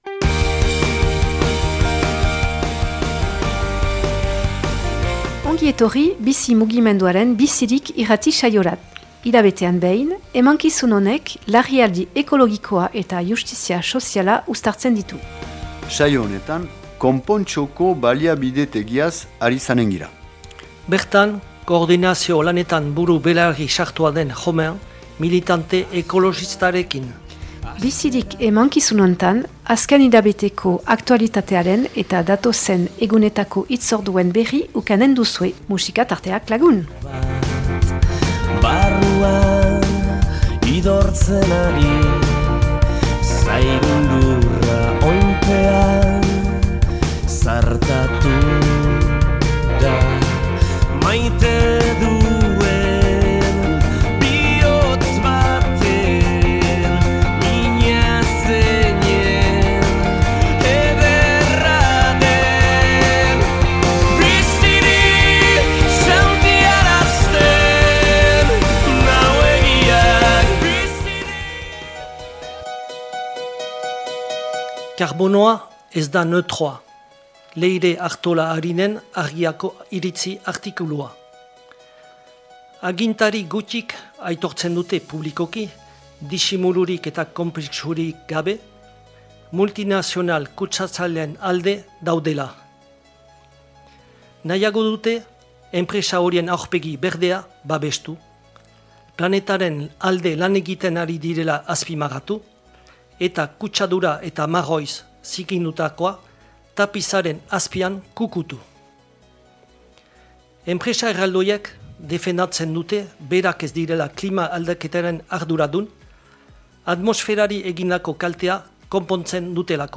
Enregistrement émission de radio en langue basque #125